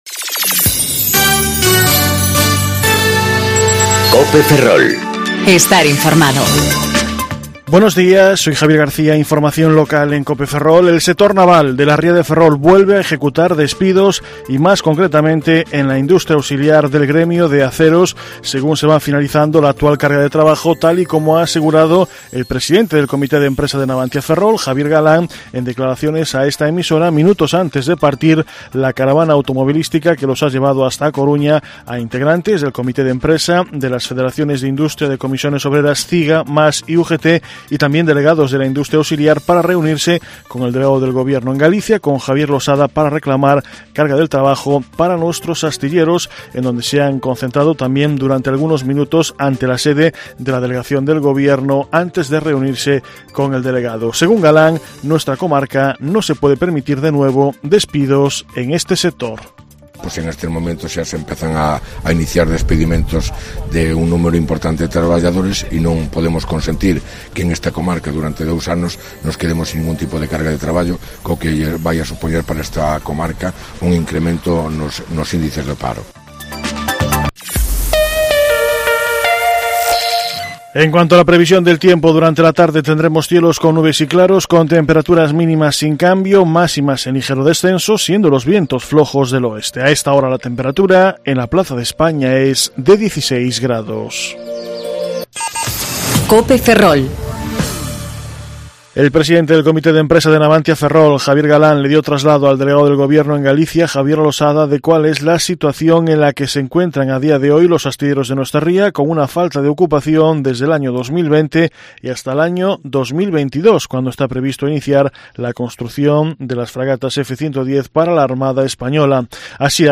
Informativo Mediodía Cope Ferrol 23/05/2019 (De 14.20 a 14.30 horas)